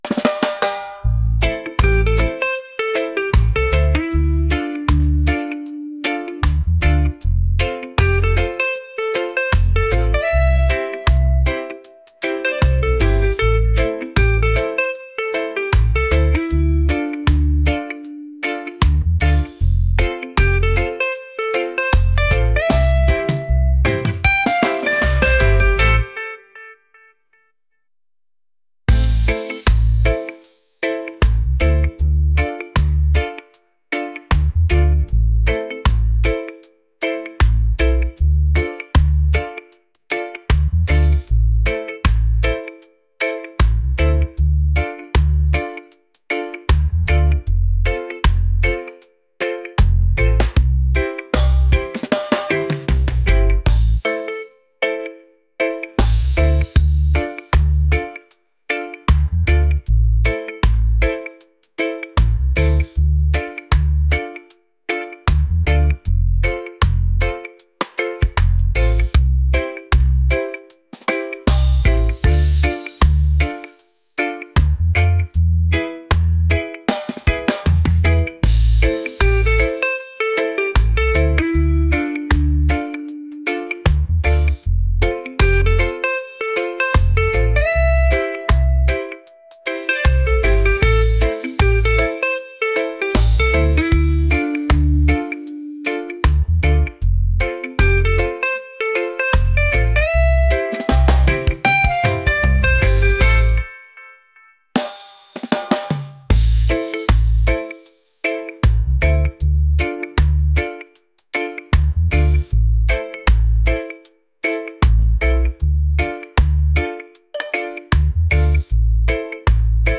reggae | laid-back | soulful